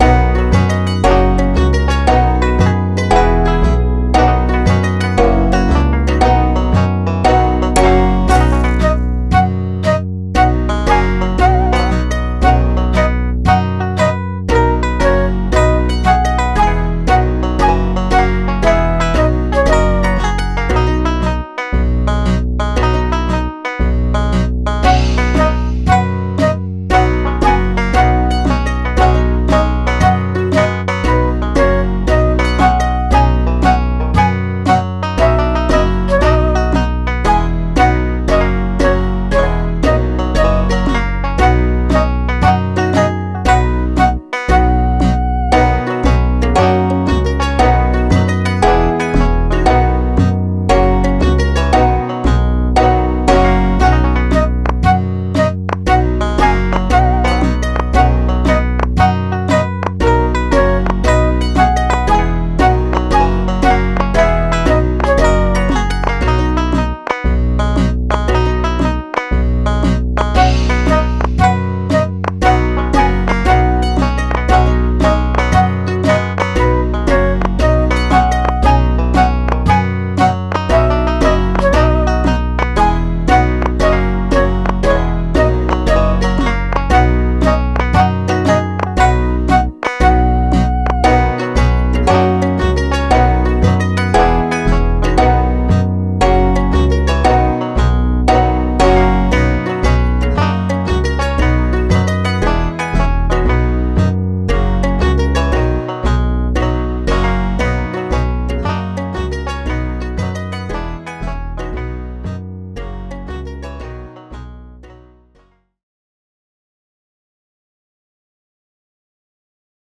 あかるい かわいい FREE BGM